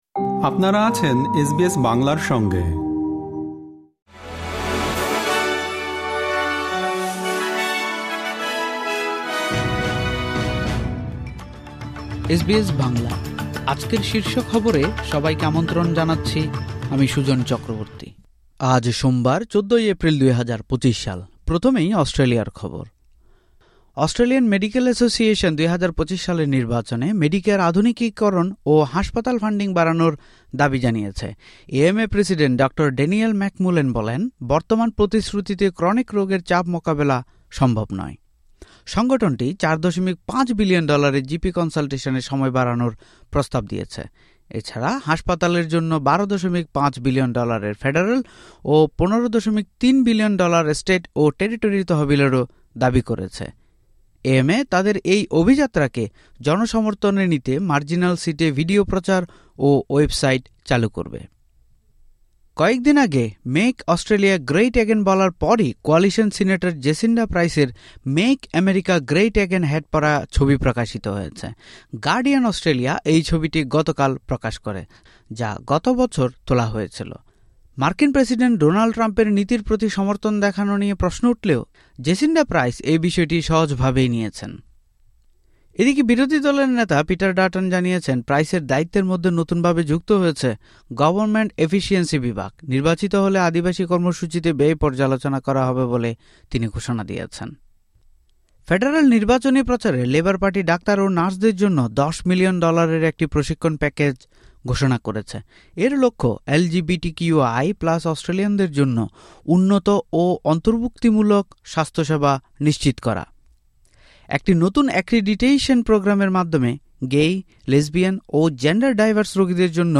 এসবিএস বাংলা শীর্ষ খবর: ১৪ এপ্রিল, ২০২৫